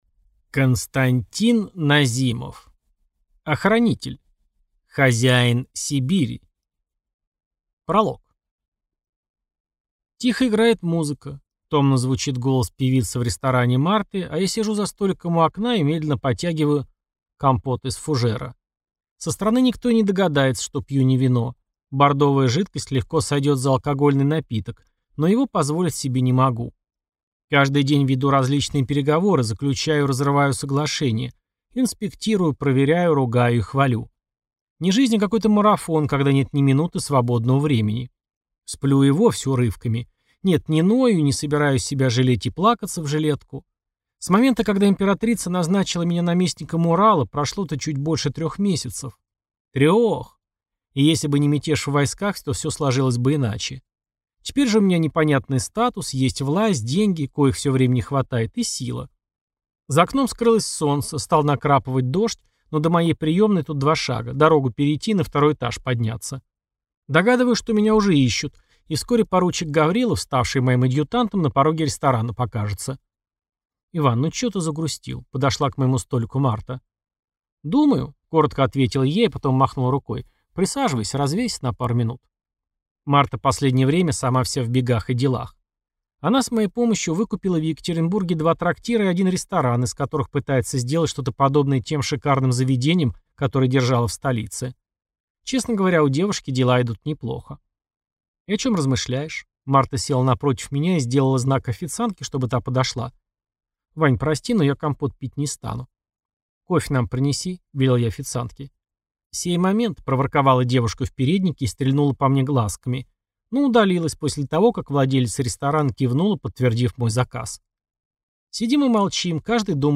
Аудиокнига Охранитель. Хозяин Сибири | Библиотека аудиокниг